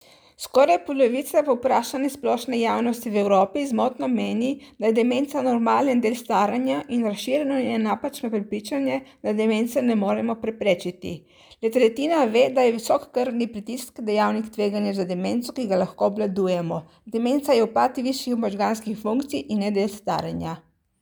Tonske izjave: